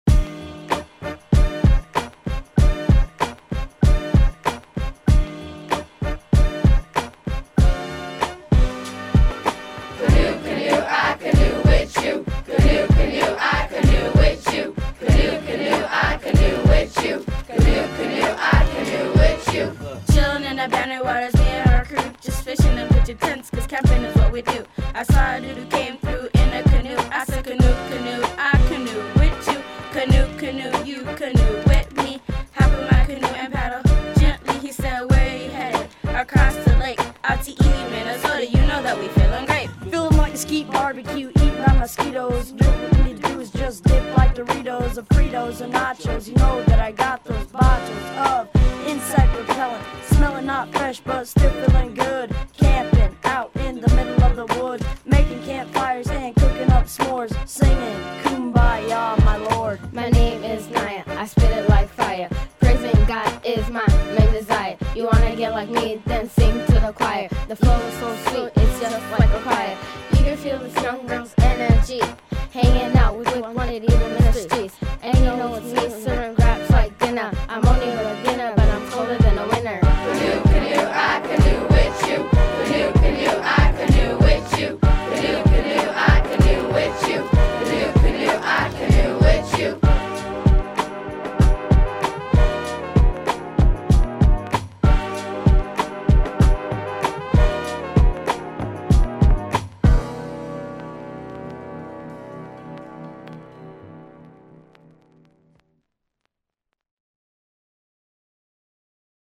A lively rap
camp     kids     minnesota     music     rap     song